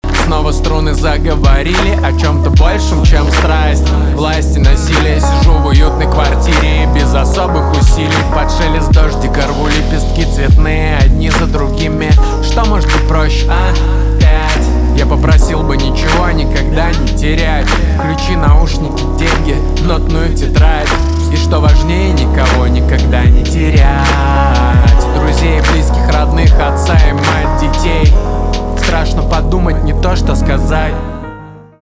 поп
чувственные
пианино